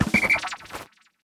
Olimar's dispand sound in Pikmin 4.